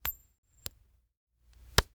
household
Coin Toss Flip and Catch 2